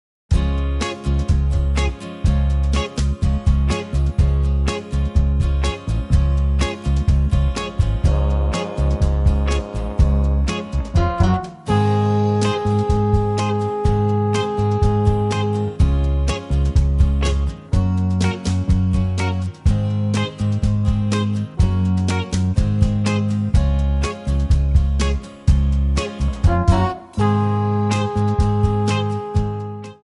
Audio Backing tracks in archive: 9793
Buy With Backing Vocals.